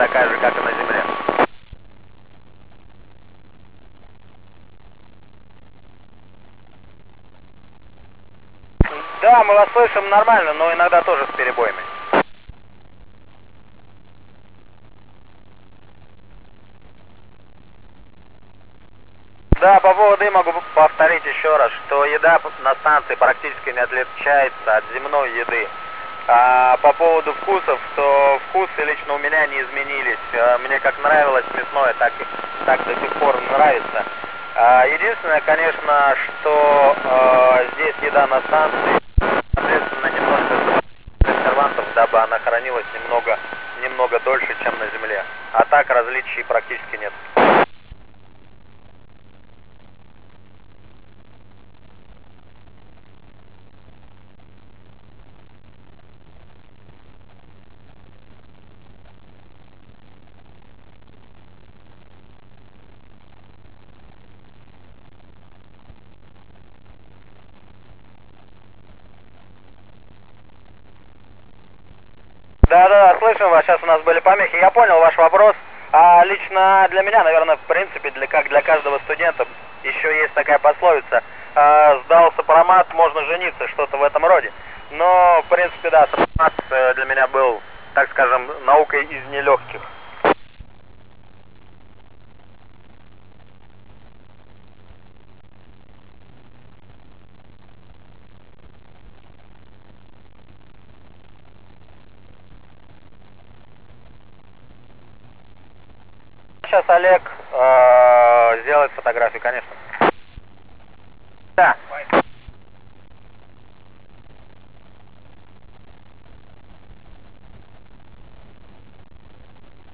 Начало » Записи » Записи радиопереговоров - МКС, спутники, наземные станции